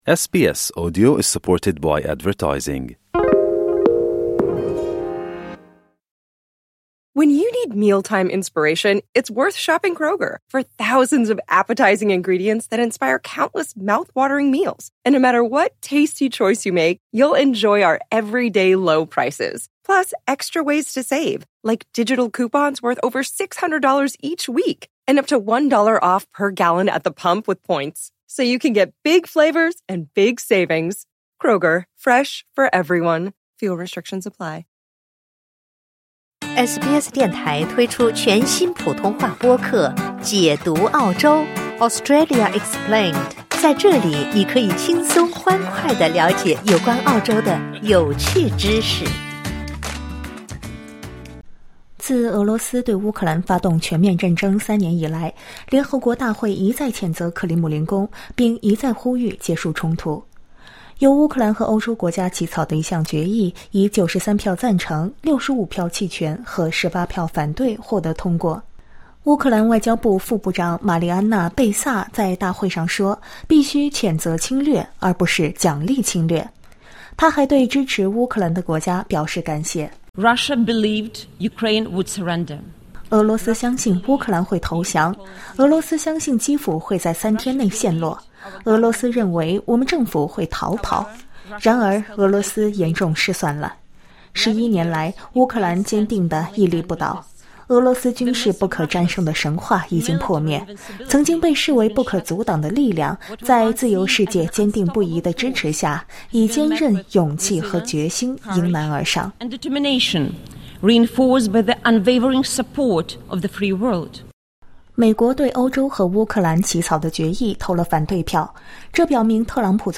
请点击收听音频报道： LISTEN TO 联合国通过谴责俄罗斯发动乌克兰战争的决议 美国投弃权票 SBS Chinese 25/02/2025 07:06 Play 欢迎下载应用程序SBS Audio，订阅Mandarin。